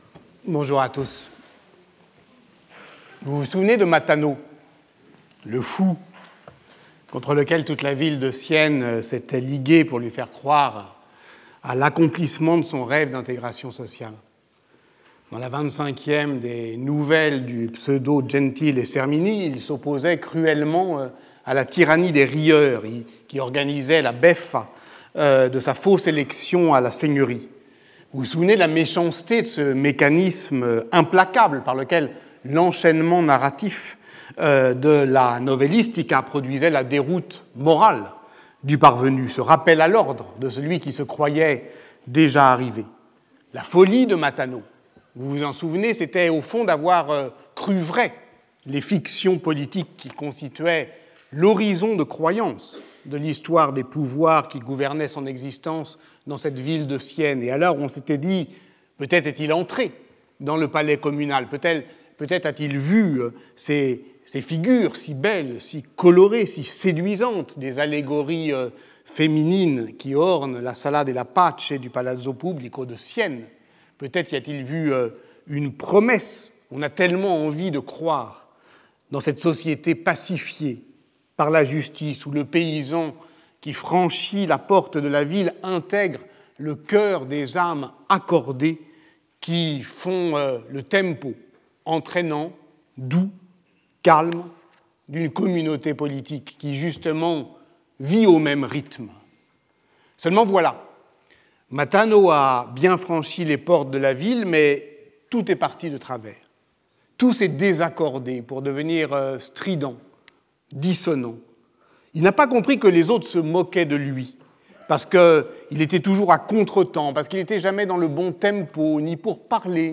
Patrick Boucheron Professeur du Collège de France